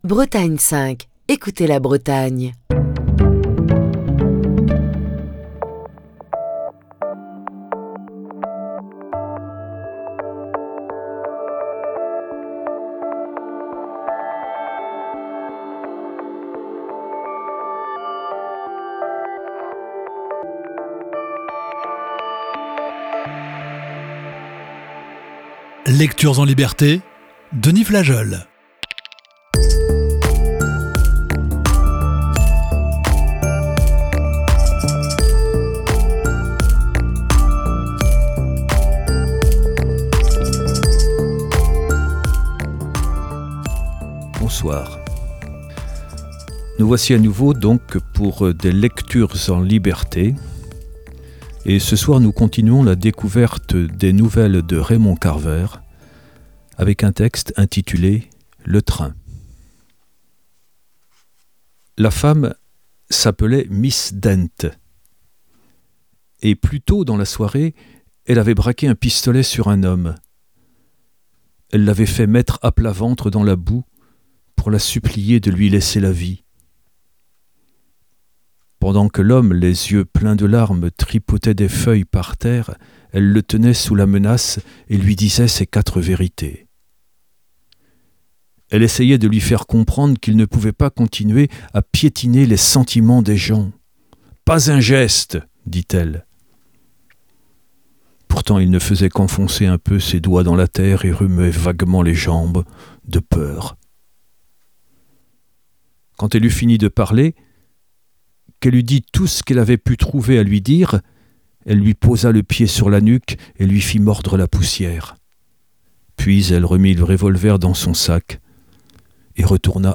Émission du 27 octobre 2023.